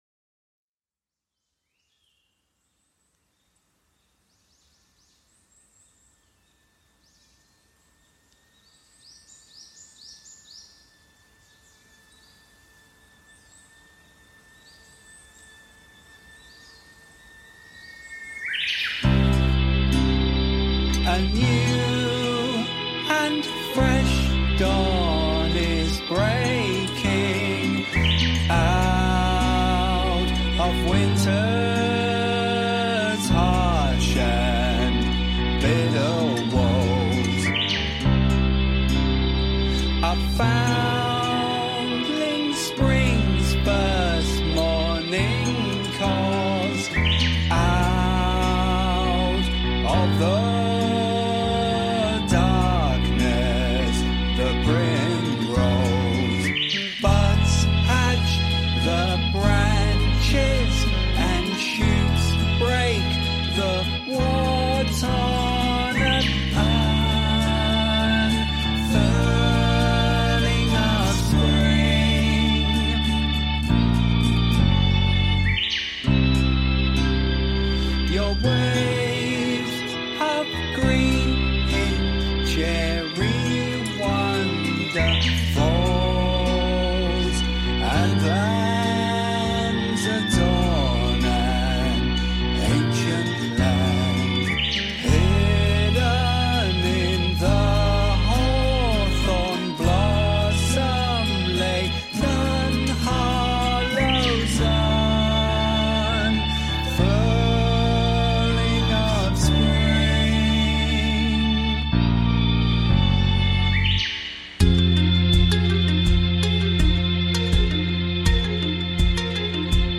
The deer hooves act as percussion in some parts, the nightingale brings rhythm to sections and there is a repeated calling at the end of most phrases.
Deer and nightingales in Nara reimagined